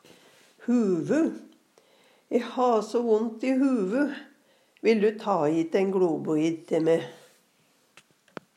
huvu - Numedalsmål (en-US)